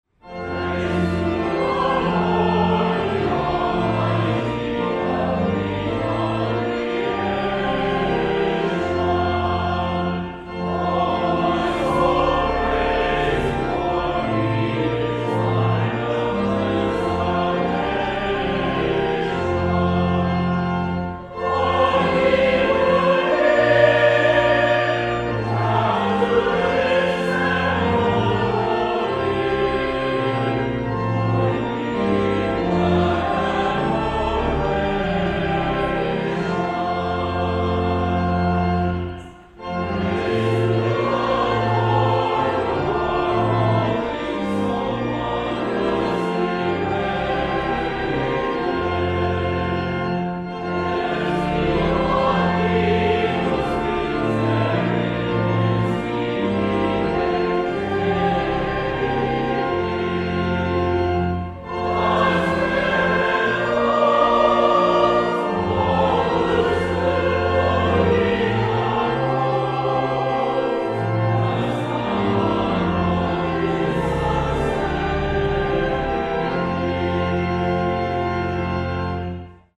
Organ
Built during the first half of 1929, Opus 805 of the Skinner Organ Company is an extremely important instrument in the city of Lancaster.
Otterbein Congregation and Organ
praise-to-the-lord-otterbein-organ.mp3